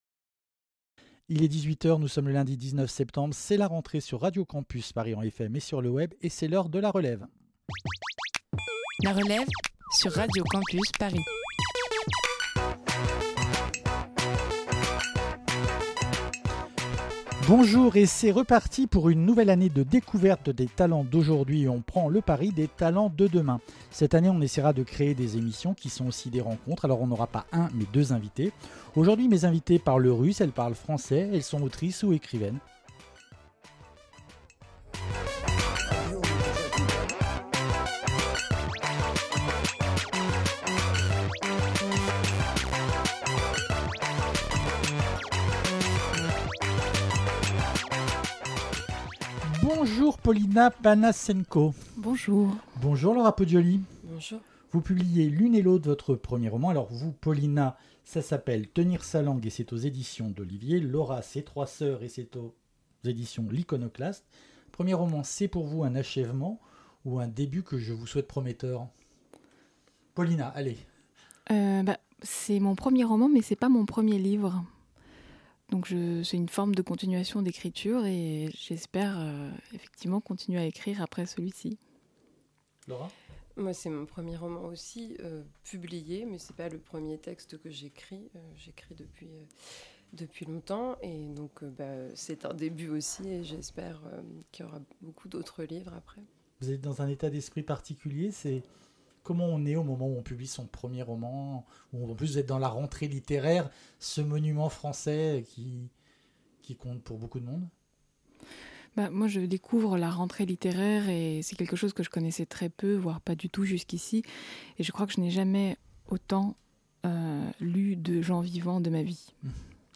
Pour cette reprise on a reçu deux primo autrices qui ont publié deux romans entre lesquels d'étranges échos se font.
Entretien